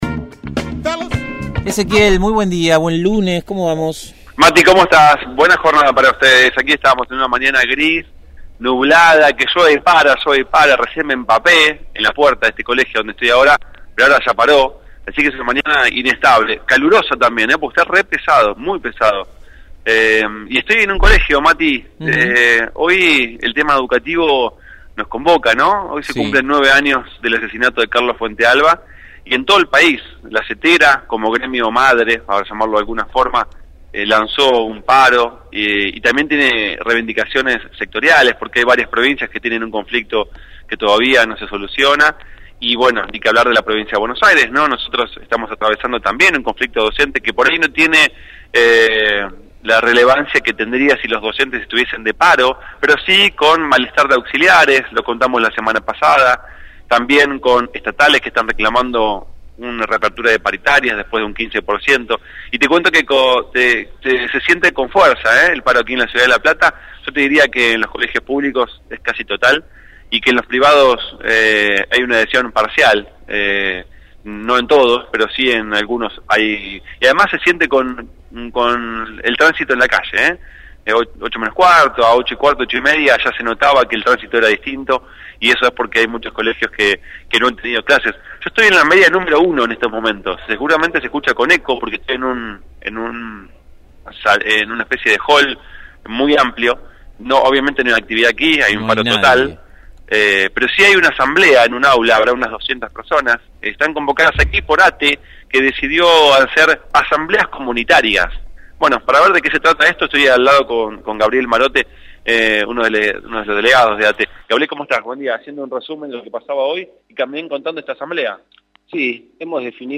MÓVIL/ Asambleas docentes en el marco del paro nacional